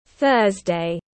Thứ 5 tiếng anh gọi là thursday, phiên âm tiếng anh đọc là /ˈθɜːz.deɪ/
Thursday /ˈθɜːz.deɪ/